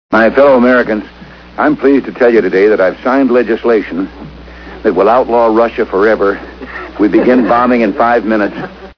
A microphone test that was accidently broadcast - this actually put the USSR on nuclear alert - WAV File